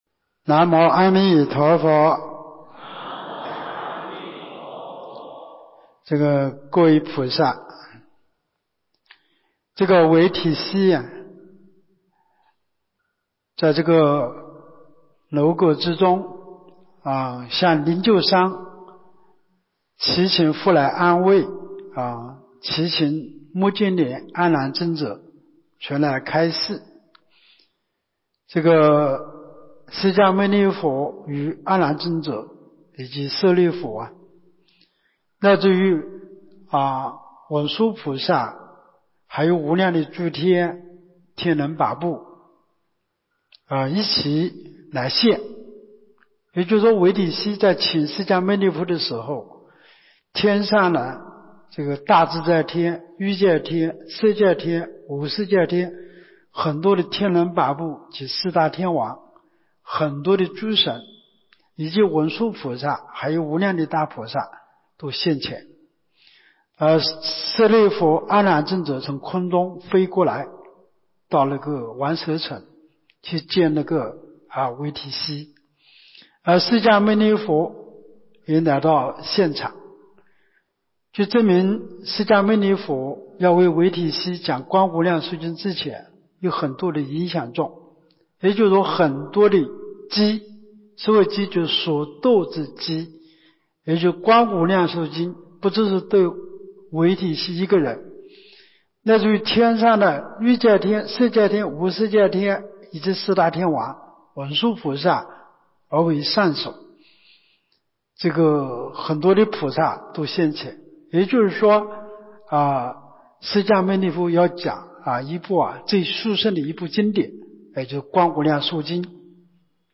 24年陆丰学佛苑冬季佛七（二）